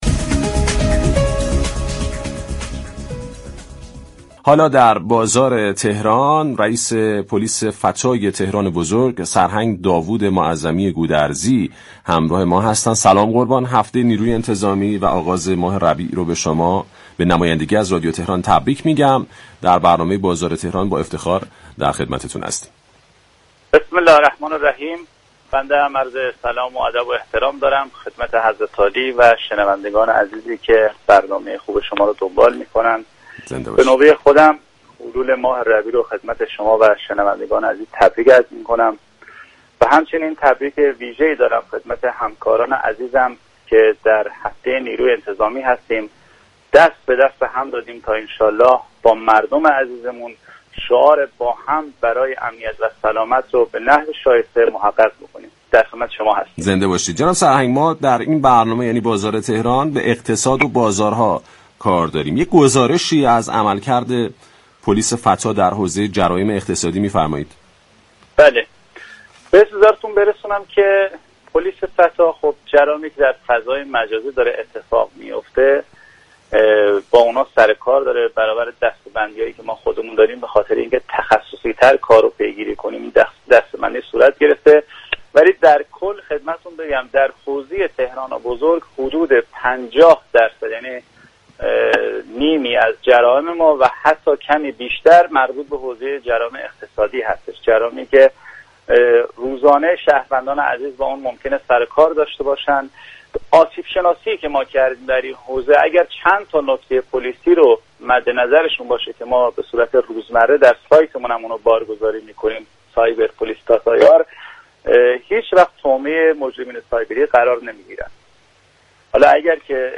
سرهنگ داود معظمی گودرزی در گفتگو با بازار تهران ضمن تبریك حلول ماه ربیع الاول و هفته نیروی انتظامی گفت: پلیس فتا با جرایم فضای مجازی سر و كار دارد؛ اما در تهران بیش از نیمی از جرایم متعلق به جرایم اقتصادی هستند.